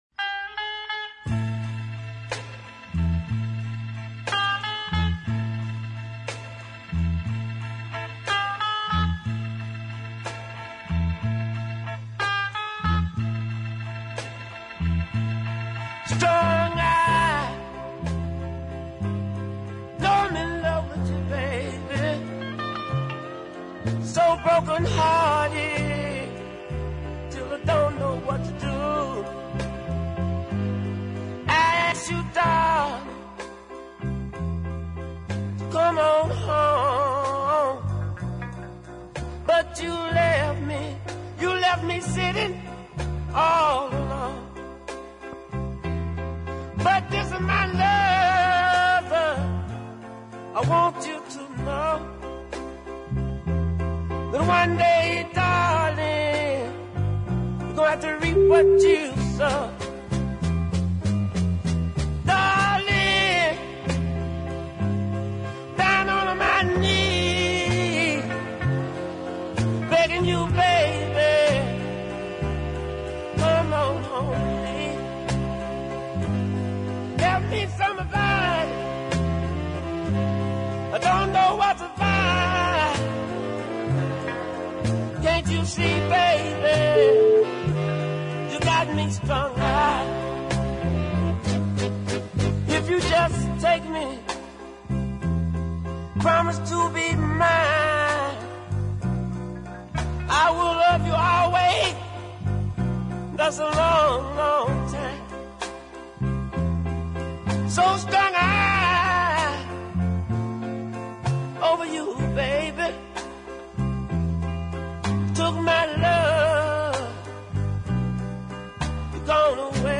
as his voice cracks with emotion – lovely.